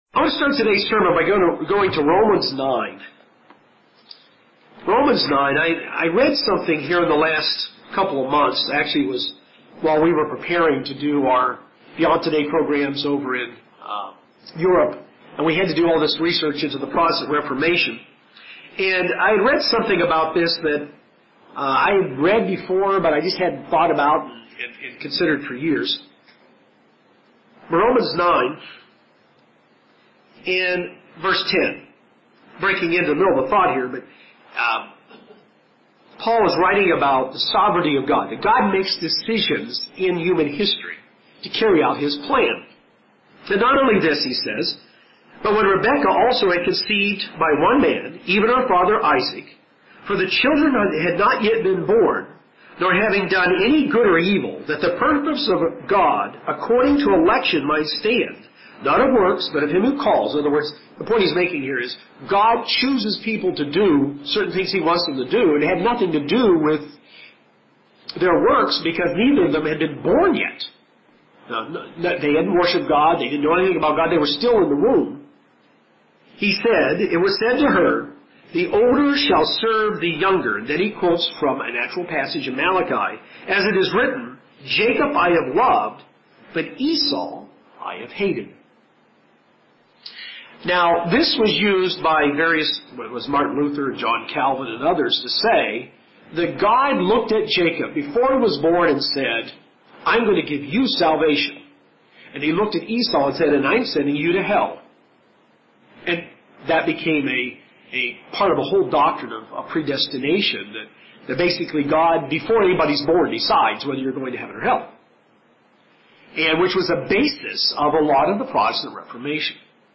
God's purpose for Israel is laid out across the ages in this sermon. It all began with one family and expanded out to an entire nation.